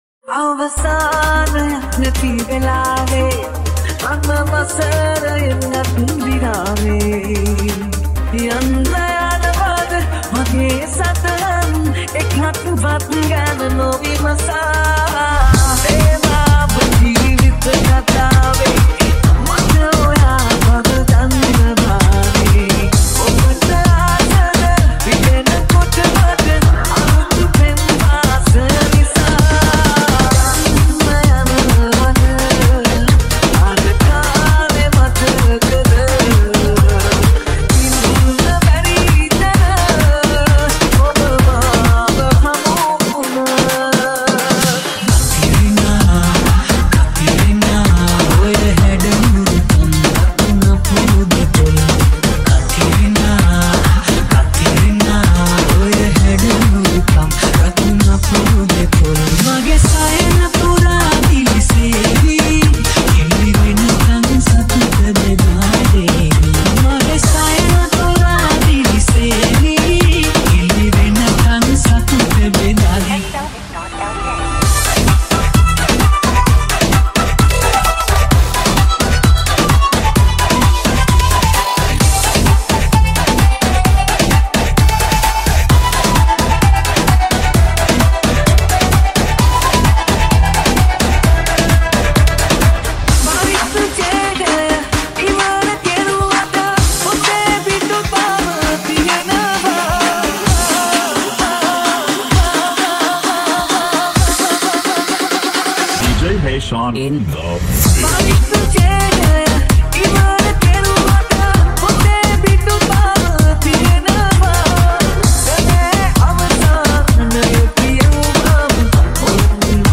Sinhala Mashup
Remix Songs